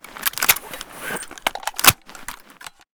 m4t_reload.ogg